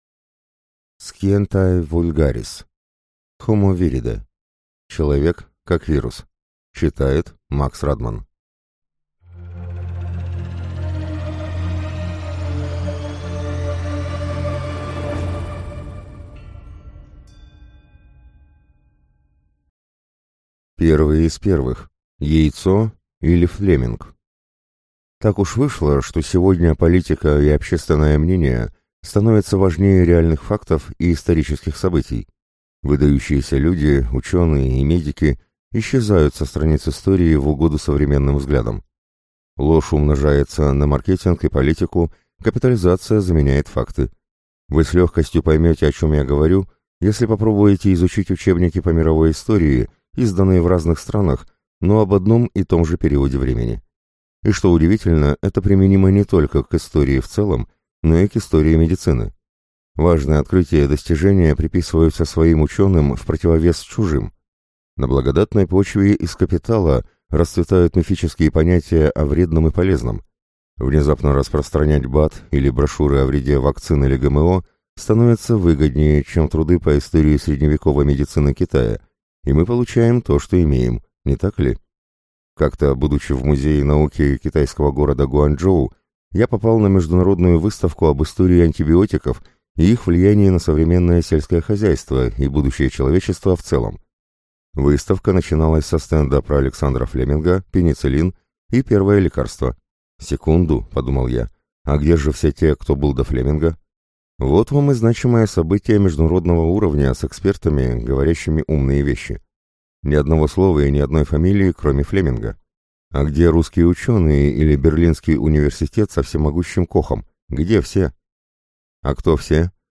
Аудиокнига Homo Viridae: человек как вирус | Библиотека аудиокниг